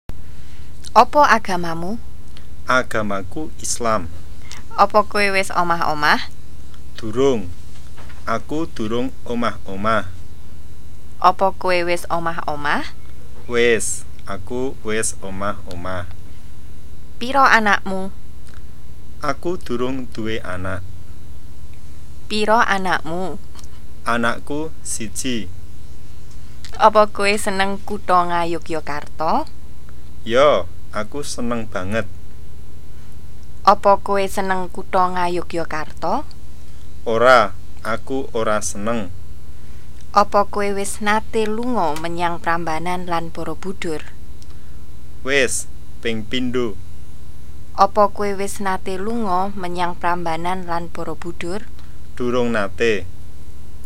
C. 1. SPECIAL CONVERSATION (NGOKO)